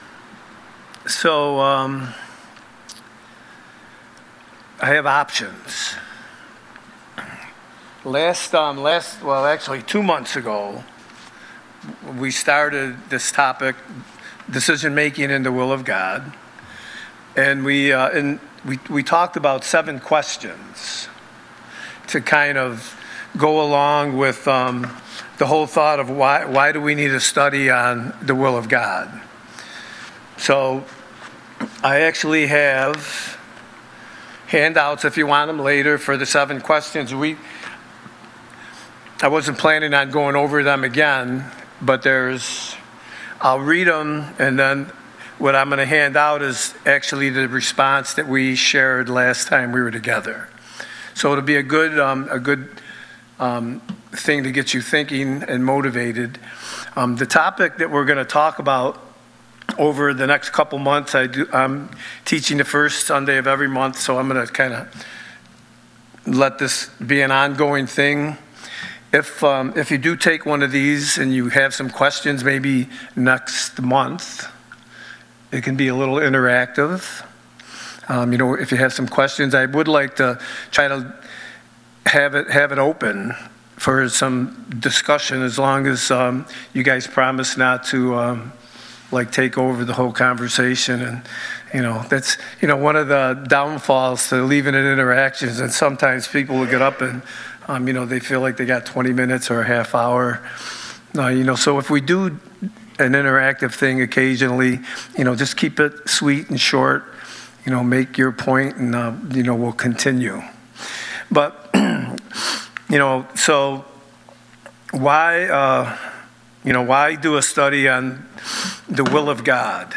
Sunday Night Bible Study